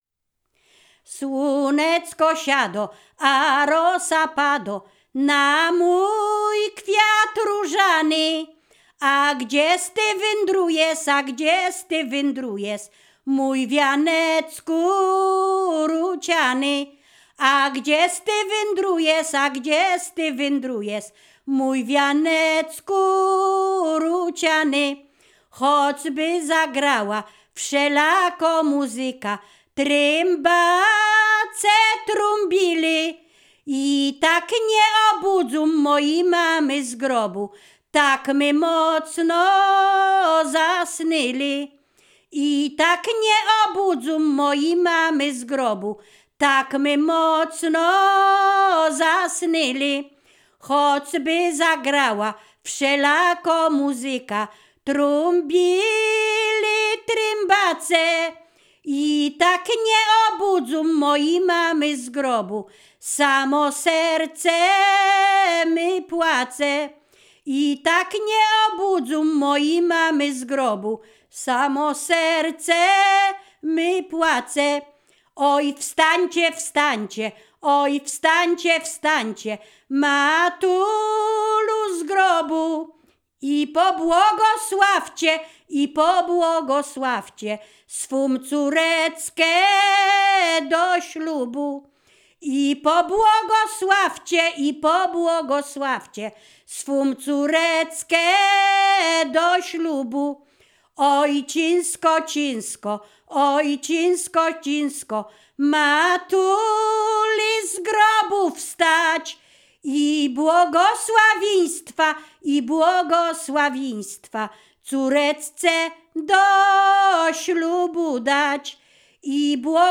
performer
Ziemia Radomska
miłosne wesele weselne sieroce błogosławieństwo